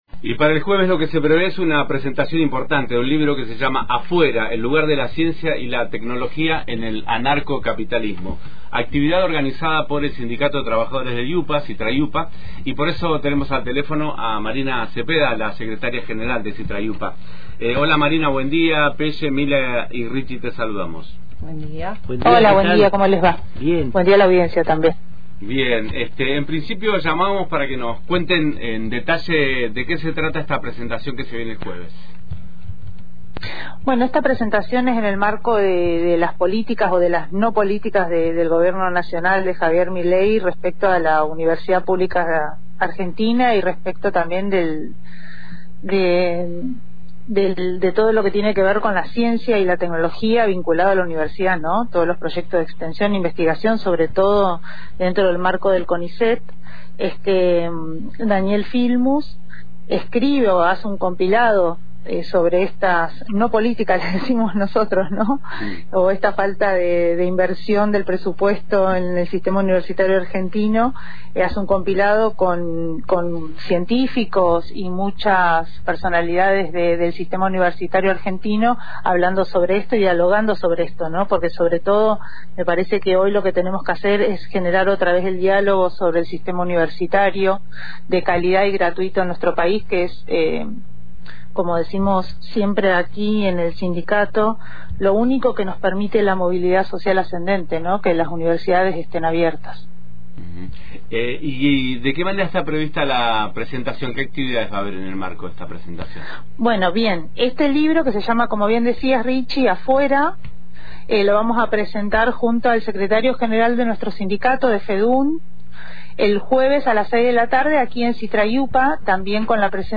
En Antena Libre conversamos